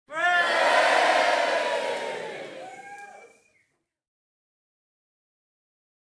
cheer.ogg